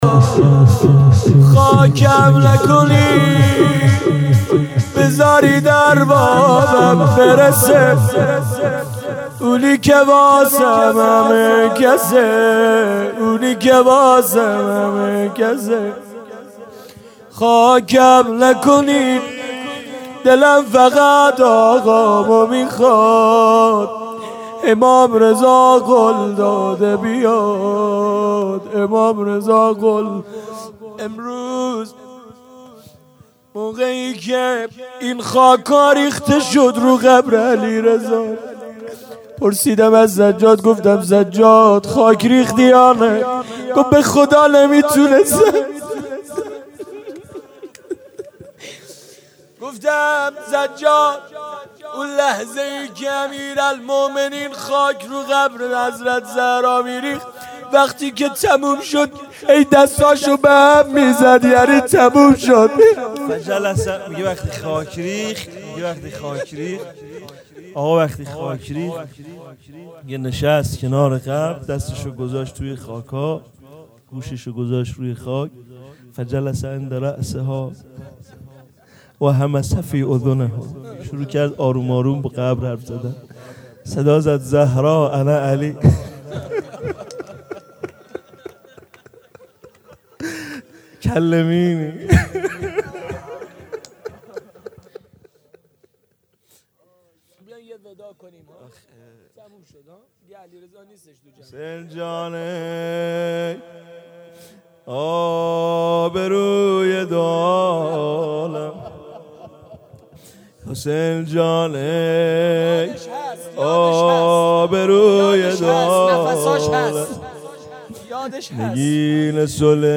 مراسم شام غریبان شهید